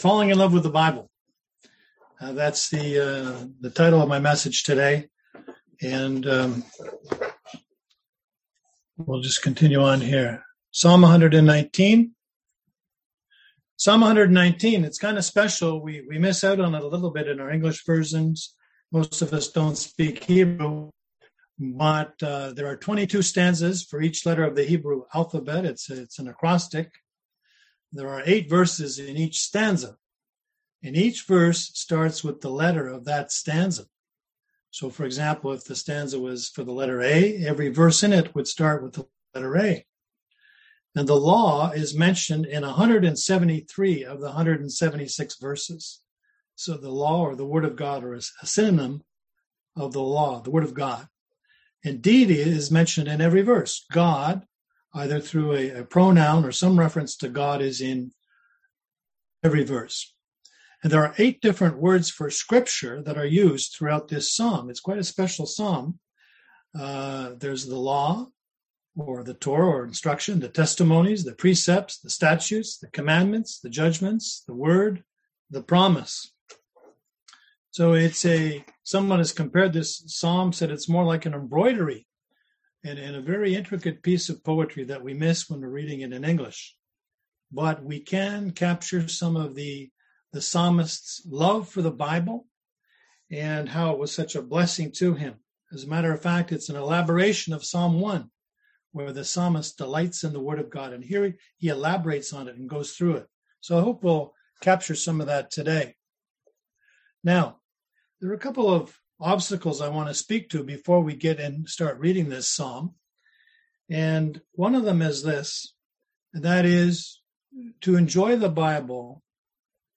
Passage: Psalm 119 Service Type: Sunday AM